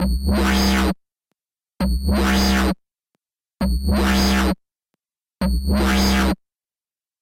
Multi-band distortion is a brilliant way to apply some heavy distortion to a sound whilst maintaining extra control over it.
Here are all three together: